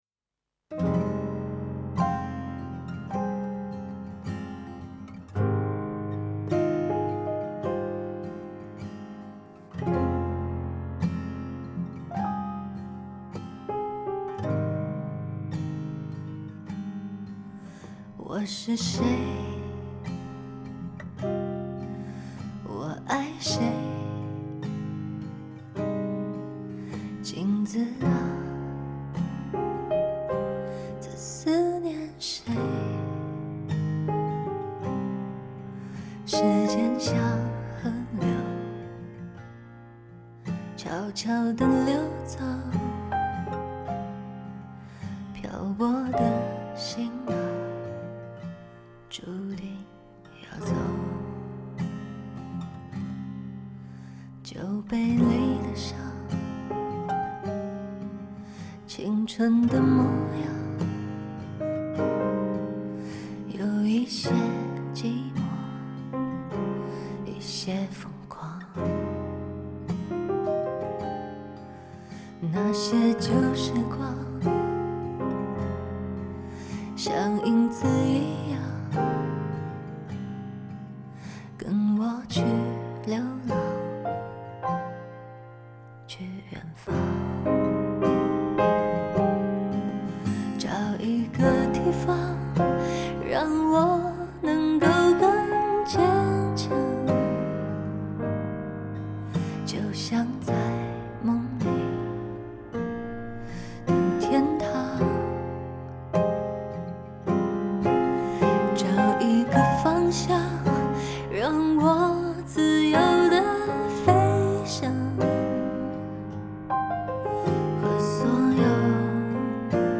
歌曲试听